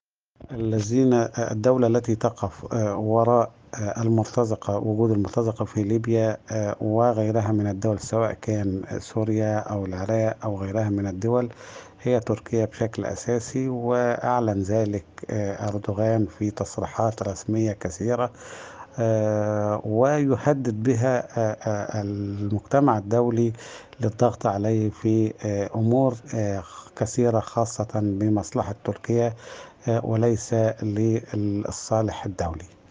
محلل سياسي وكاتب صحفي